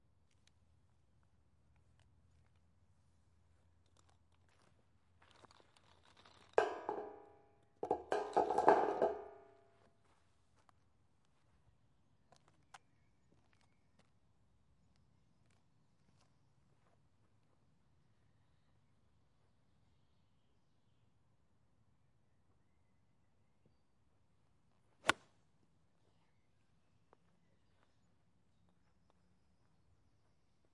体育 " HSN高尔夫五号铁杆
描述：高尔夫用Yonex的铁七打。
Tag: 挥杆 高尔夫 体育